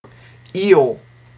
• /iʟ/ is phonetically [iːɤˁ] (